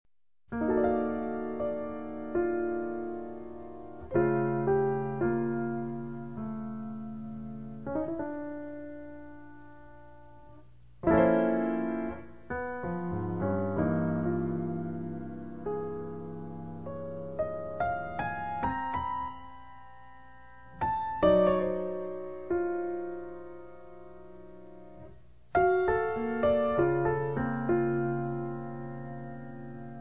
• jazz
• registrazione sonora di musica